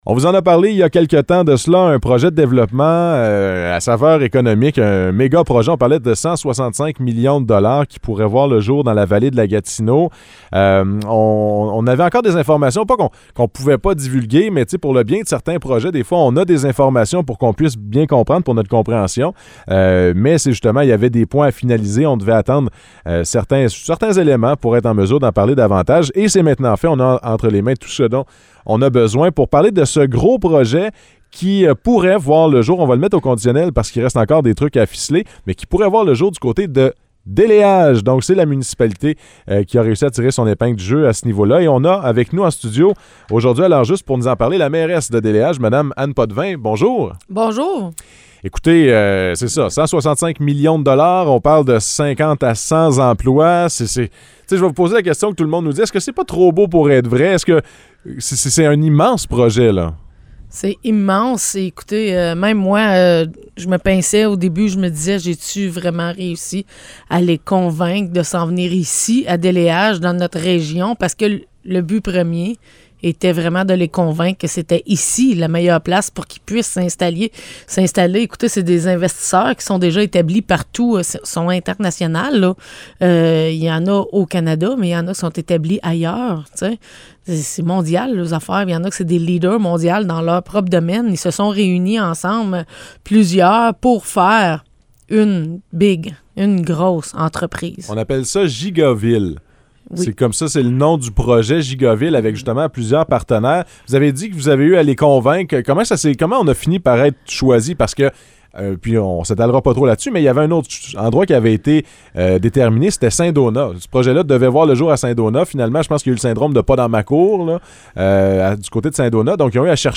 Entrevue avec Anne Potvin, mairesse de Déléage